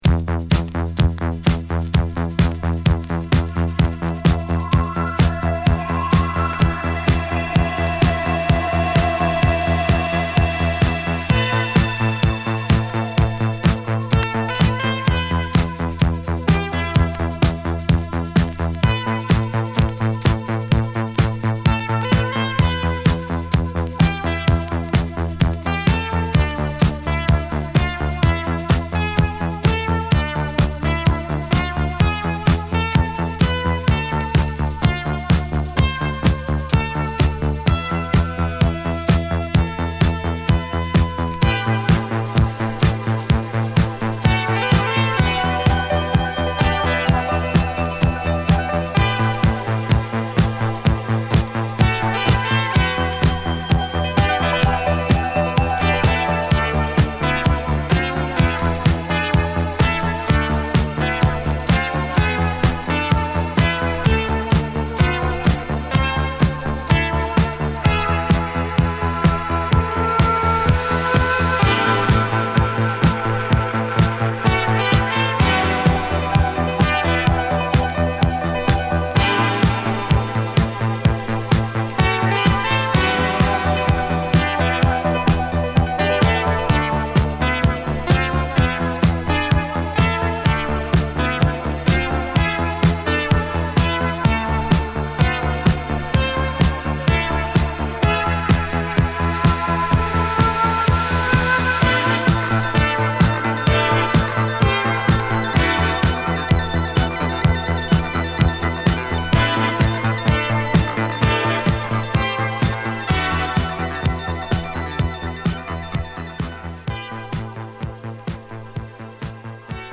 不思議なストリングスサウンドを使ったインストディスコ
共にインスト曲で
アルバムとは違うドラムとパーカッションで、ファットなボトムに生まれ変わったディスコヴァージョンです！